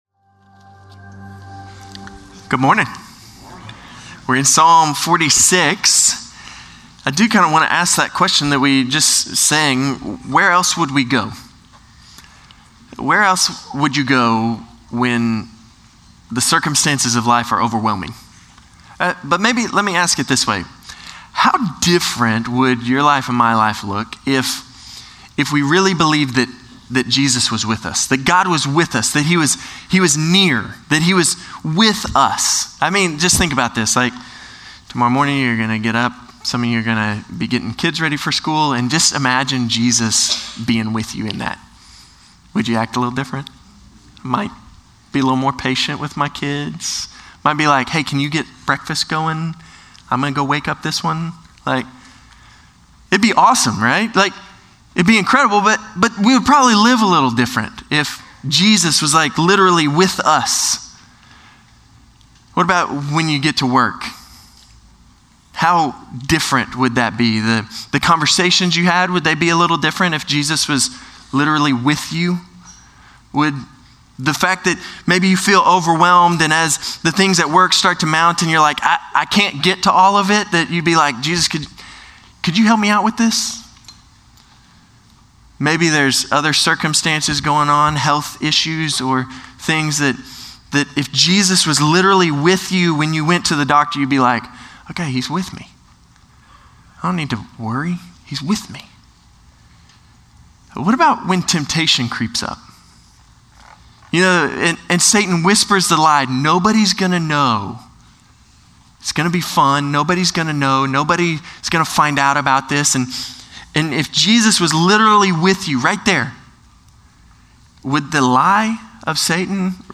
Norris Ferry Sermons Oct. 27, 2024 -- The Book of Psalms -- Psalm 46 Oct 27 2024 | 00:35:03 1x 00:00 / 00:35:03 Subscribe Share Spotify RSS Feed Share Link Embed